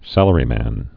(sălə-rē-măn, sălrē-)